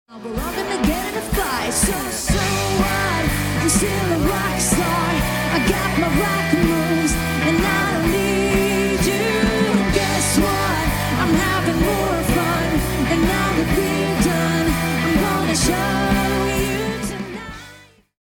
Modernt partyband med bred repertoar!
• Coverband
• Rockband
• Popband